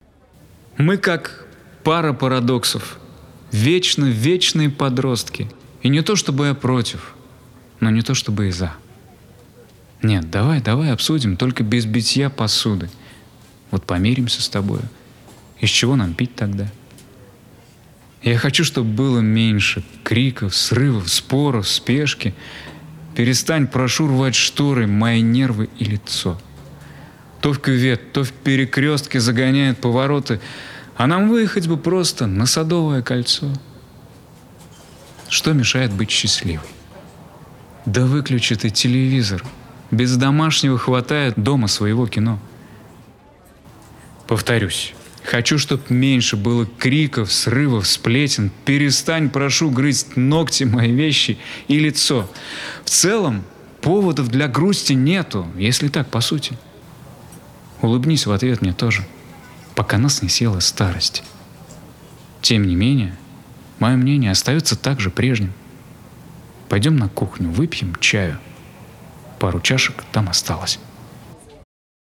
[Live]